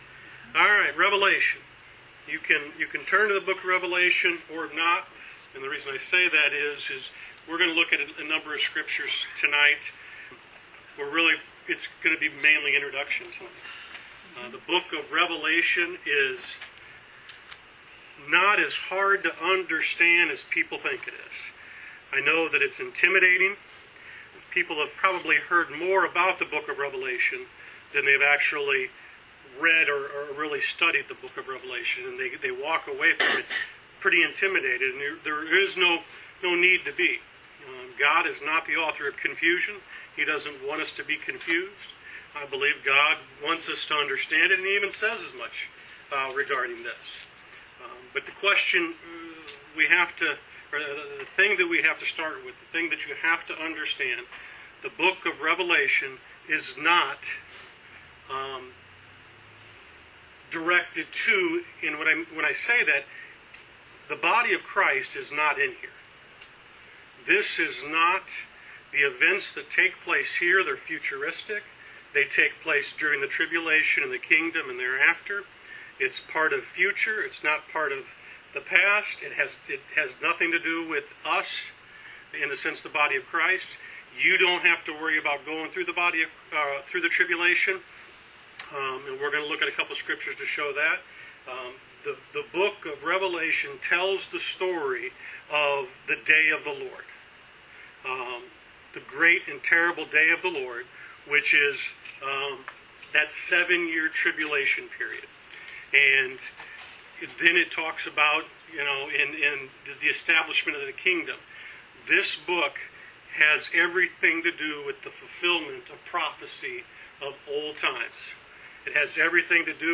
Wednesday Bible Study: Revelation Introduction